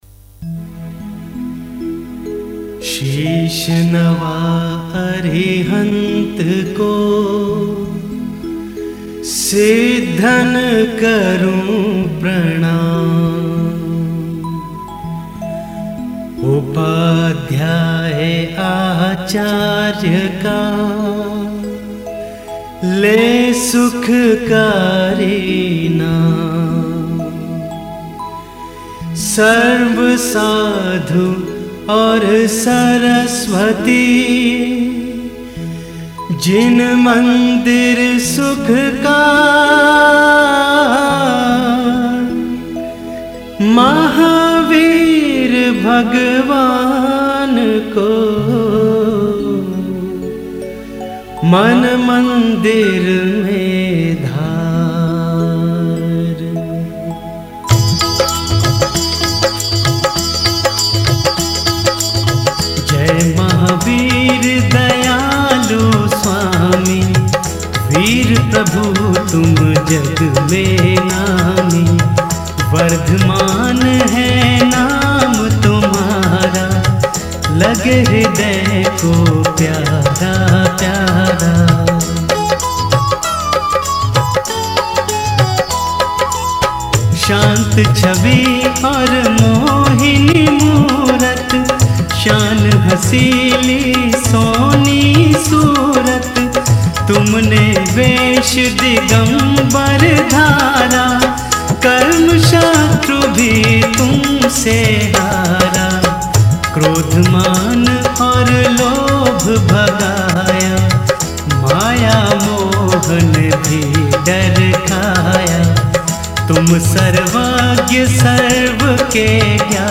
Particulars: Chalisa